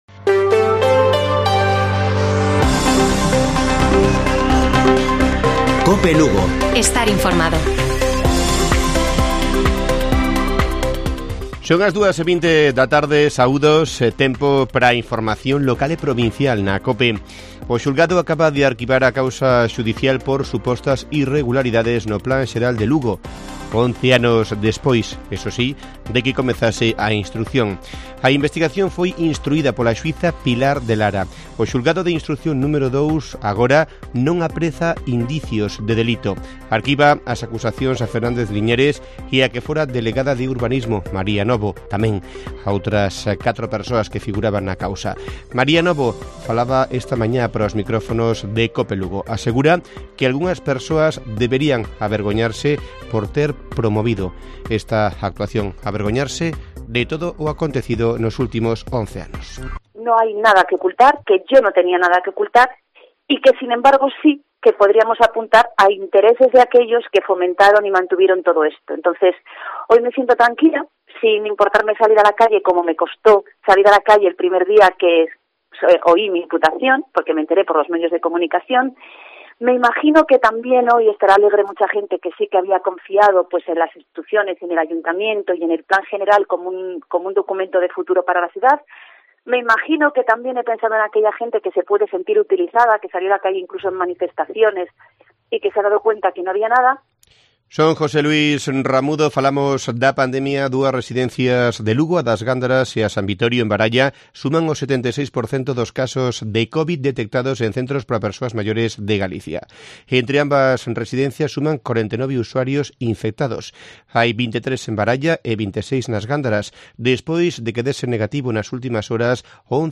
Informativo Mediodía de Cope Lugo. 03 de septiembre. 14:20 horas